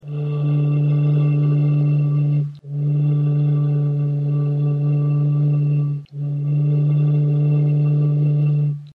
lahev.mp3